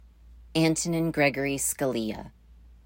1. ^ Pronounced /ˈæntənɪn skəˈlə/
AN-tən-in skə-LEE, Italian: [skaˈliːa].